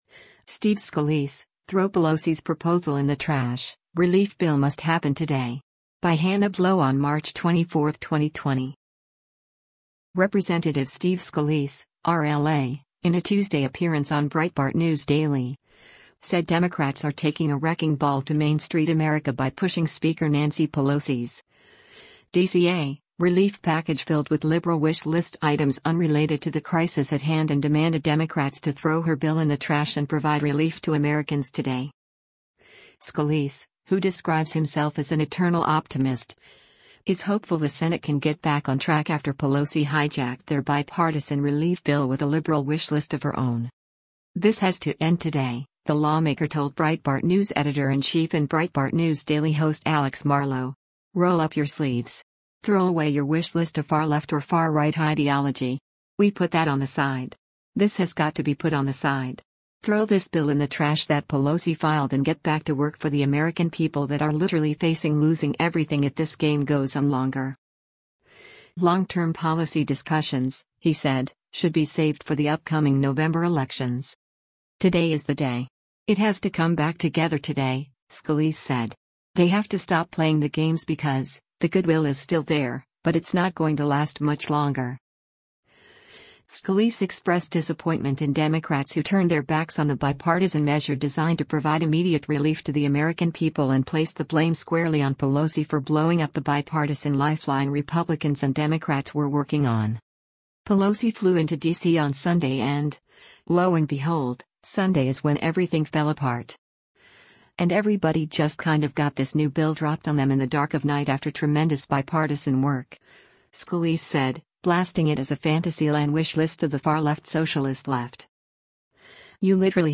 Rep. Steve Scalise (R-LA), in a Tuesday appearance on Breitbart News Daily, said Democrats are “taking a wrecking ball to Main Street America” by pushing Speaker Nancy Pelosi’s (D-CA) relief package filled with “liberal wish list” items unrelated to the crisis at hand and demanded Democrats to throw her bill in the trash and provide relief to Americans “today.”